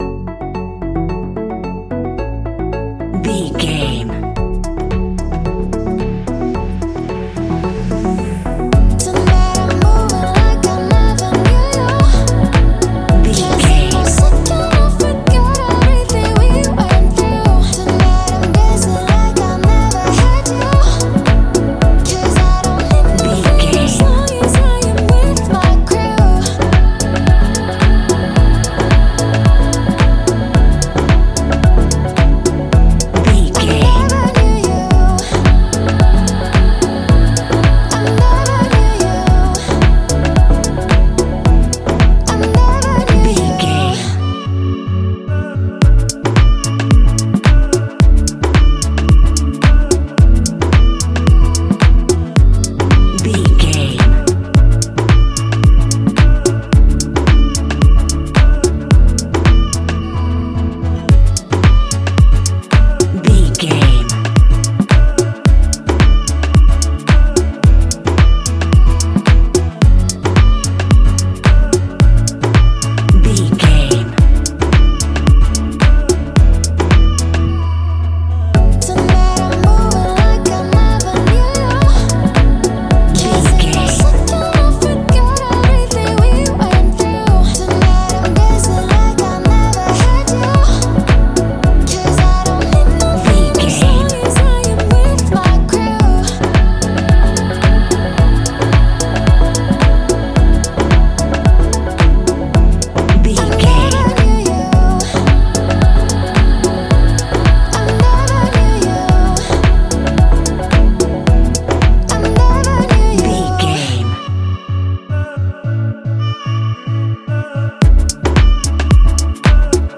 Ionian/Major
F♯
house
electro dance
synths
techno
trance
instrumentals